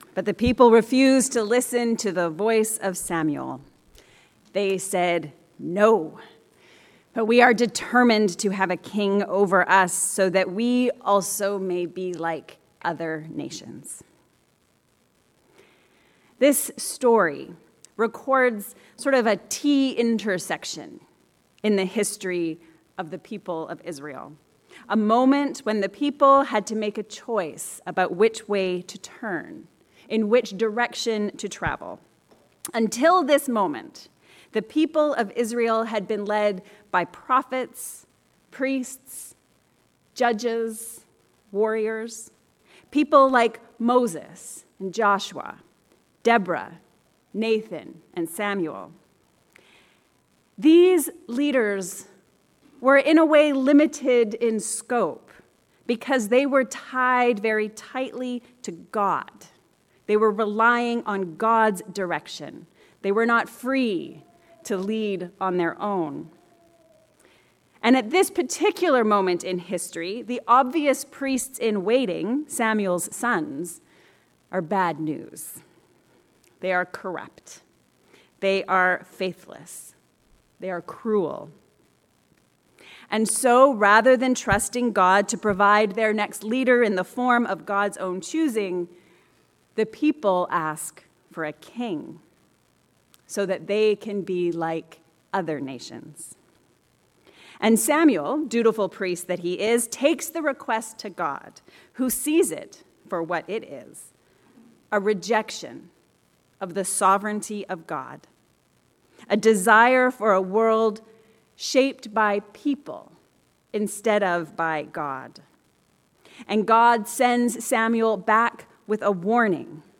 A sermon on 1 Samual 8:4-20 and Mark 3:20-35 – St. Helen's Anglican Church